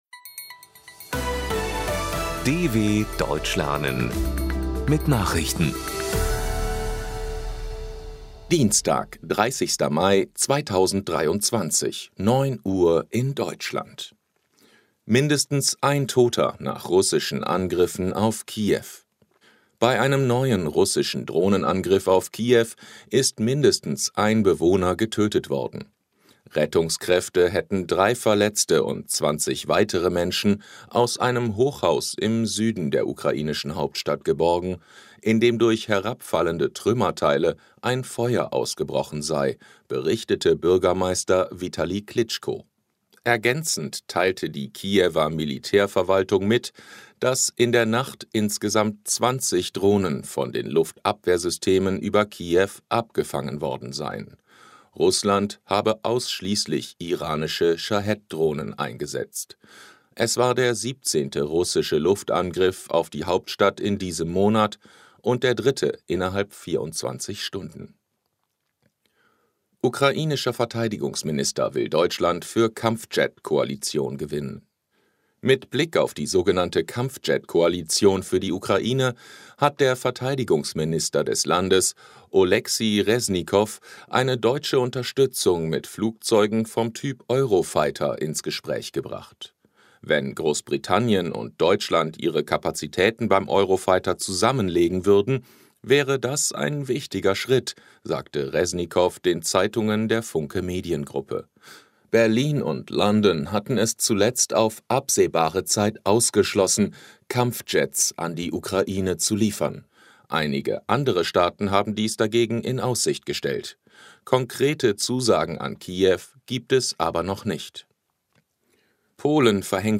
Trainiere dein Hörverstehen mit den Nachrichten der Deutschen Welle von Dienstag – als Text und als verständlich gesprochene Audio-Datei.